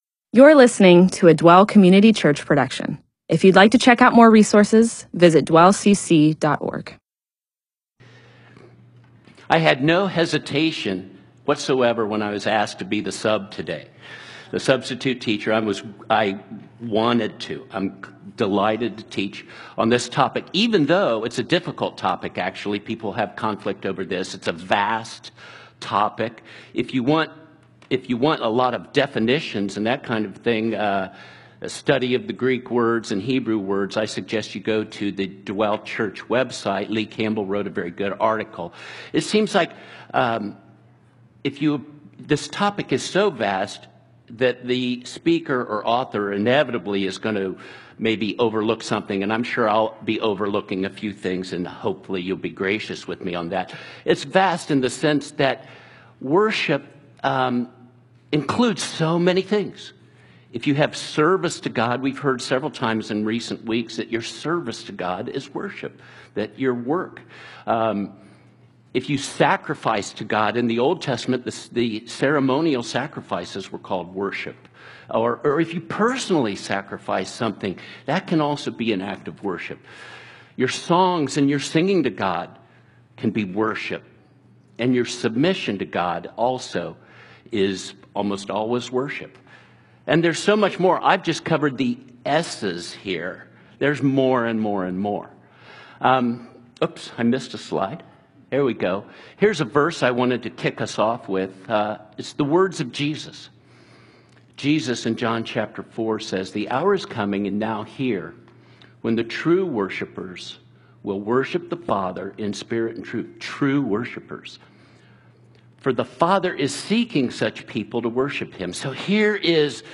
MP4/M4A audio recording of a Bible teaching/sermon/presentation about John 4:23.